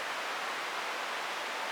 soft-sliderwhistle.ogg